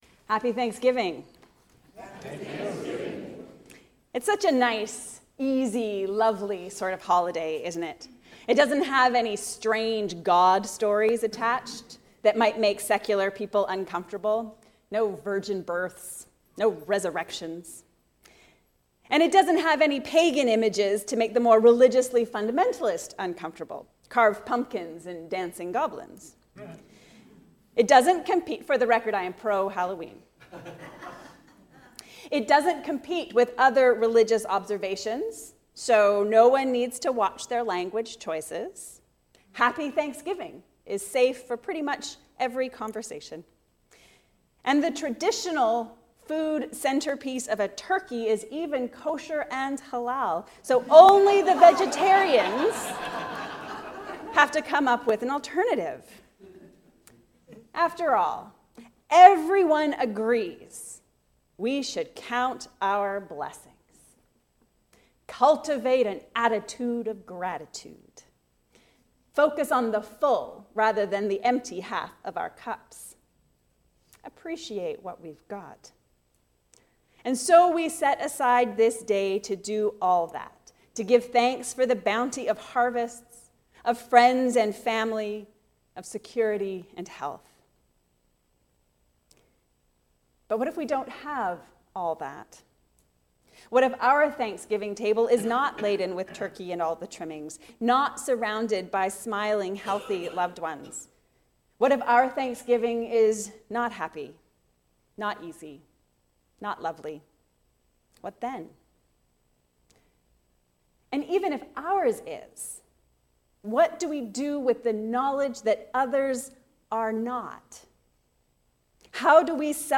Giving Thanks. A sermon on Luke 17:11-19
Sermon-8-Oct-2023.mp3